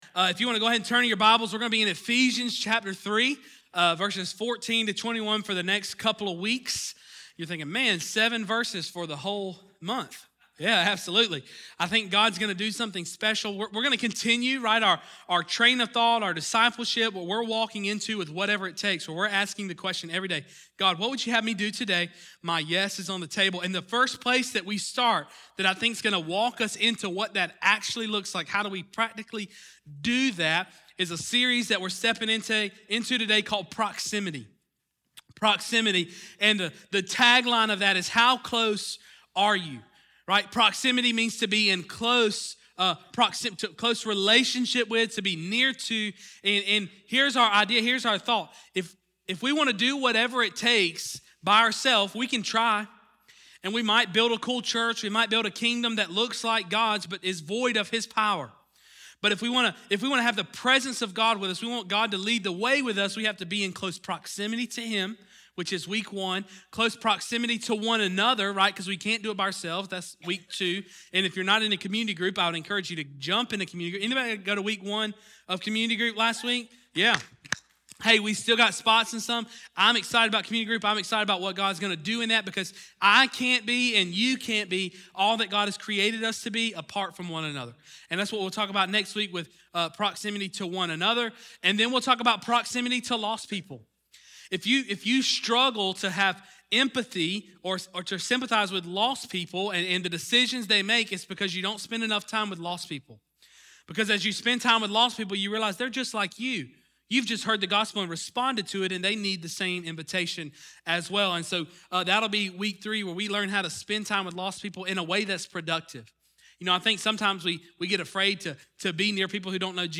Sermon Outline Proximity to God Discussion Guide Discussion Questions 1.